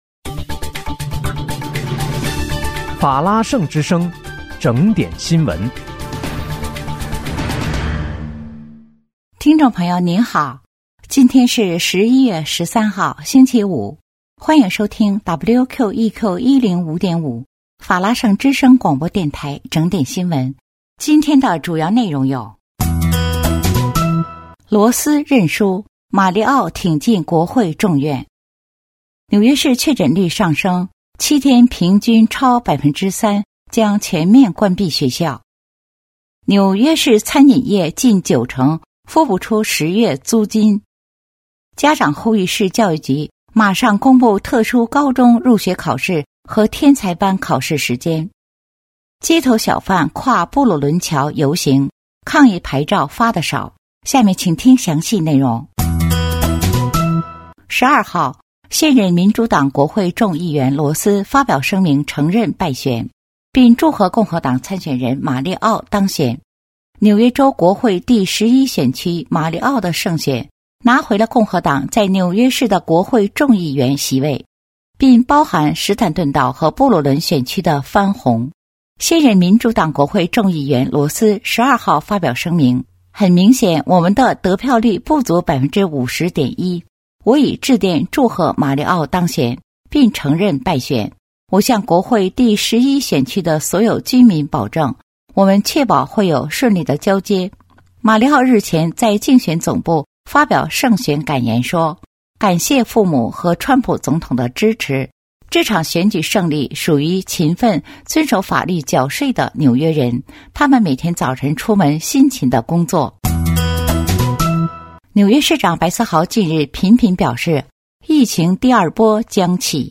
11月13日（星期五）纽约整点新闻